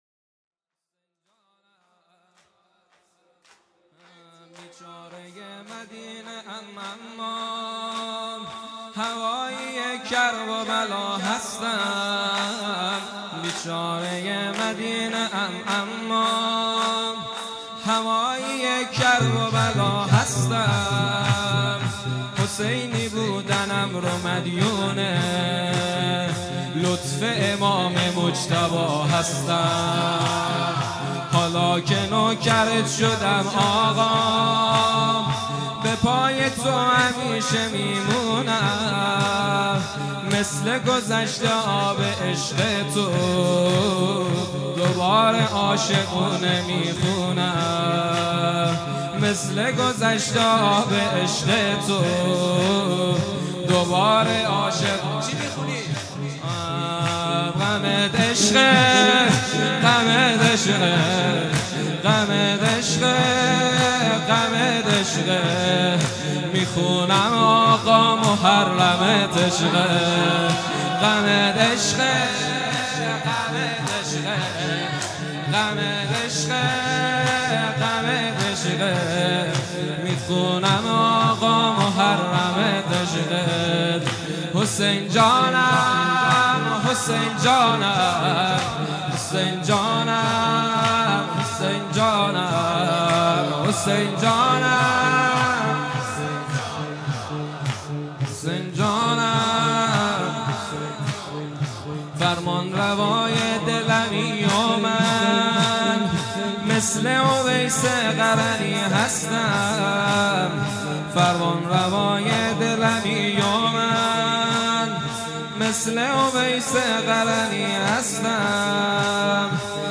غمت عشقه|جلسه هفتگی ۱۷ اردیبهشت ۹۸